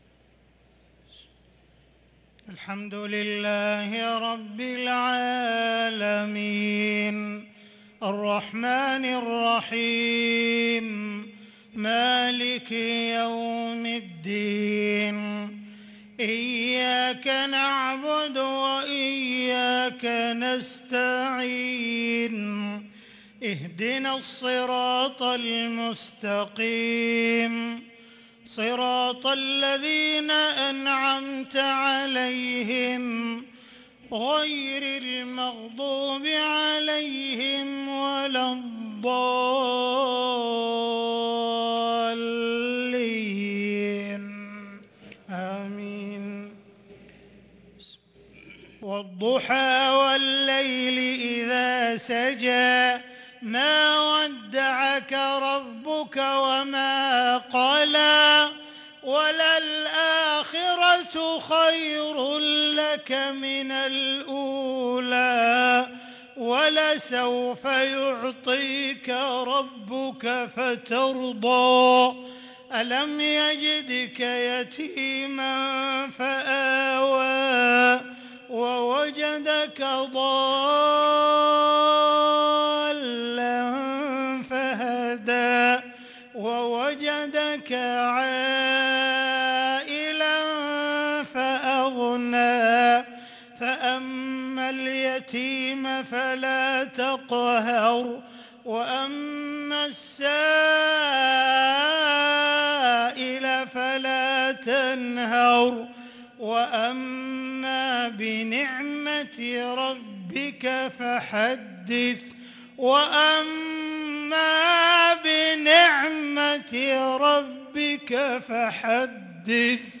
صلاة المغرب للقارئ عبدالرحمن السديس 21 رمضان 1443 هـ
تِلَاوَات الْحَرَمَيْن .